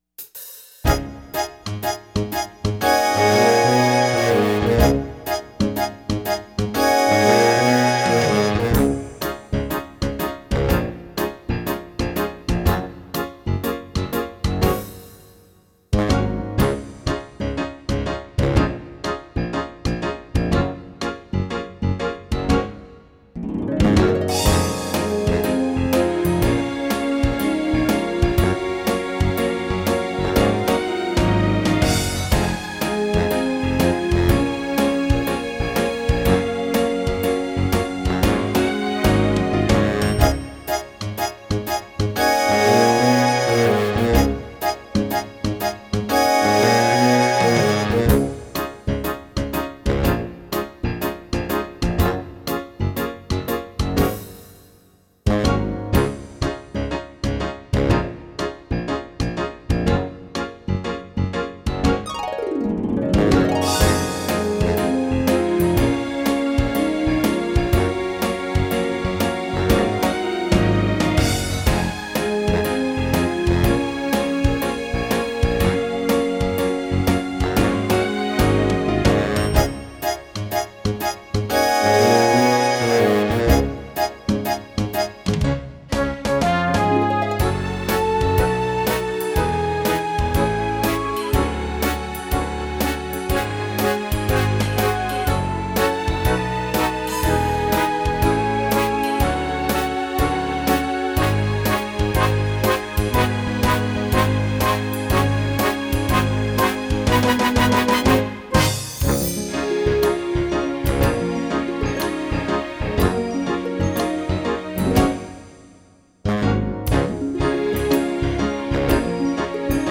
For whatever reason, the tune I came up with is one of the catchiest I’ve ever written – just one of those lucky gifts – instantly hummable and very perky.
And just listen to that rideout at the end – if this were in a musical today, the idiots would end it with a button directly after the final “more” – but when you build like that, you have to let the “more” sing out, Louise because it’s emotionally correct – and then when you put the tune in the rideout it’s like the whipped cream and cherry on a hot fudge sundae.